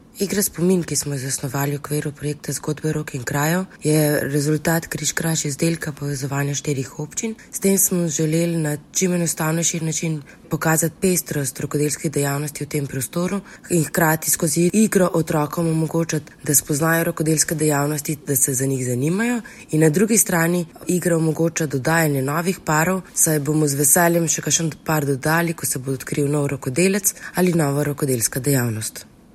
tonska izjava